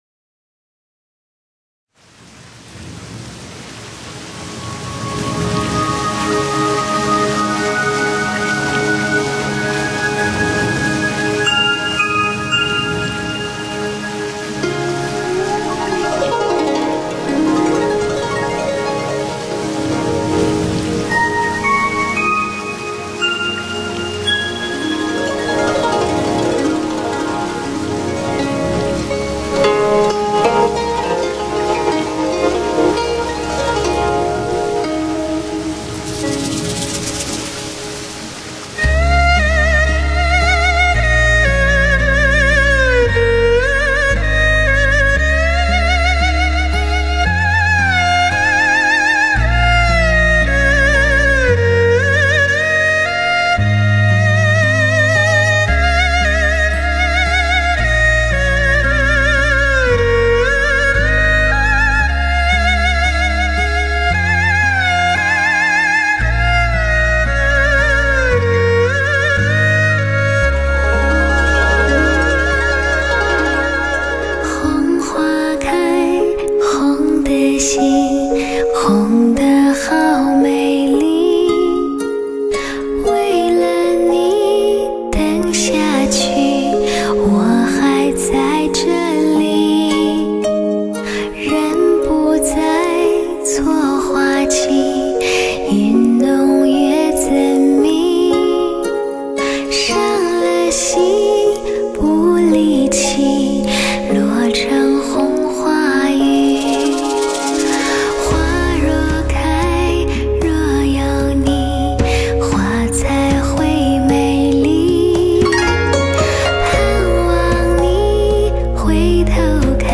丝丝入扣的细腻音质华丽绽放
迭起的绝美颤音引人浮想
柔美细腻的女音，凭借领先国际的DTS多声道环绕和多重混响技术，带给你极尽奢华的磁性音质和绝无仅有的听觉享受。